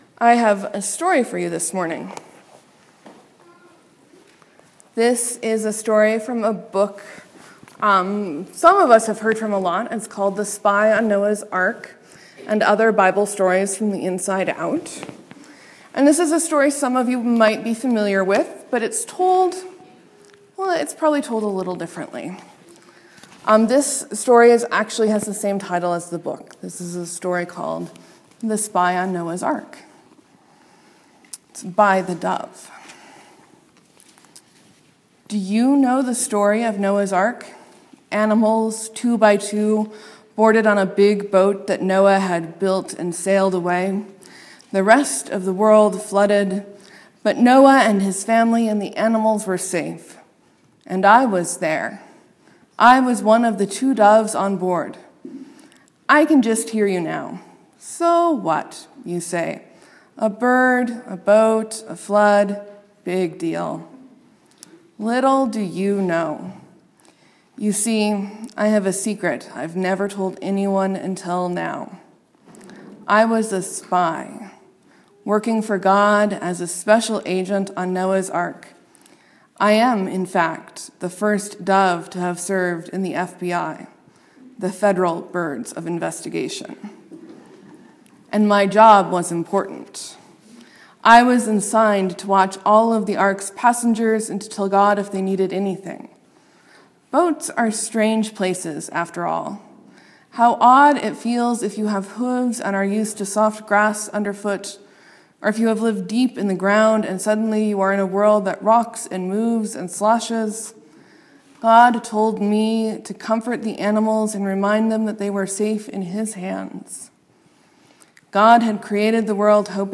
Morsels & Stories: I read “The Spy on Noah’s Ark” from The Spy on Noah’s Ark: Bible Stories From the Inside Out